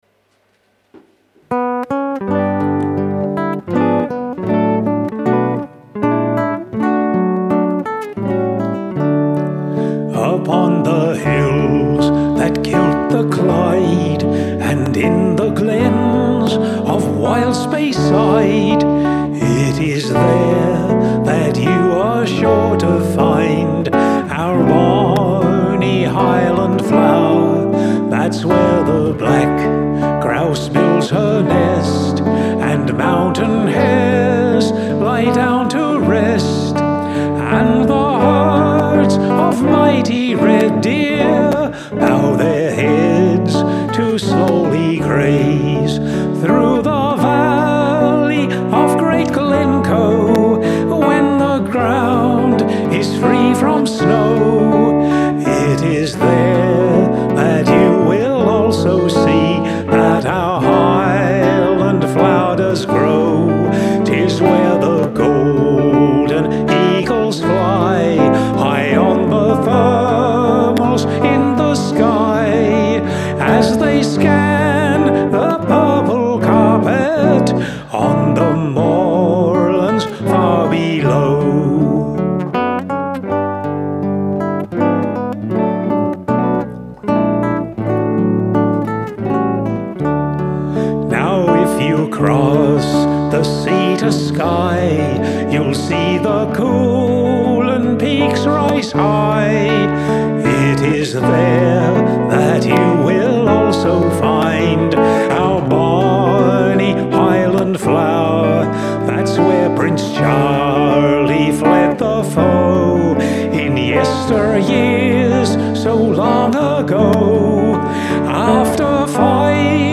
Vocals
Guitar/Bass